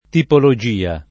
[ tipolo J& a ]